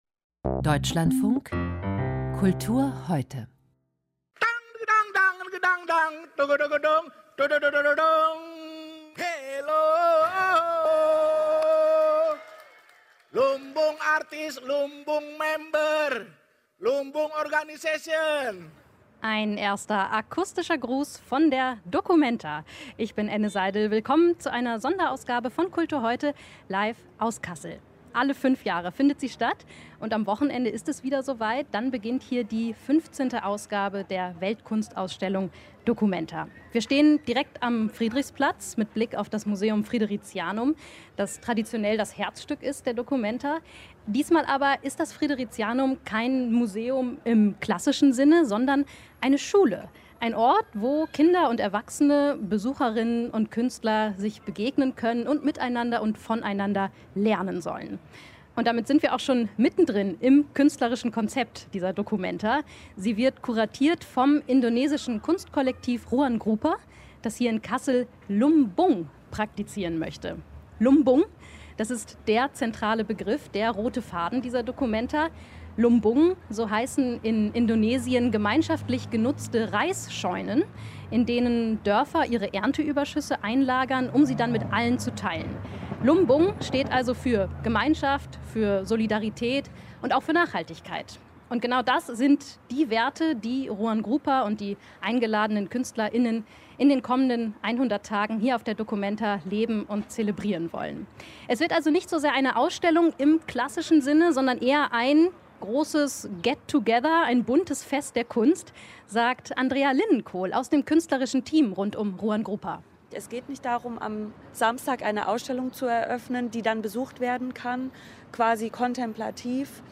Kultur heute: Sondersendung documenta 15 - live aus Kassel